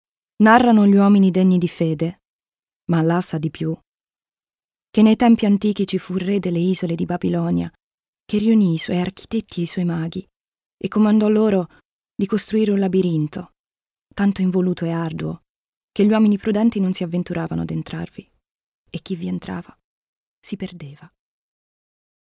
Italienische Sprecherin/Synchronsprecherin, deutsch mit Akzent, Werbung,Hörbuch,Fitness,Industrie,e-lerning,Hörspiel, Voice-over, Moderatorin.
Sprechprobe: Sonstiges (Muttersprache):